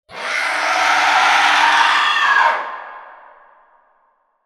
Download Scary Sound sound effect for free.
Scary Sound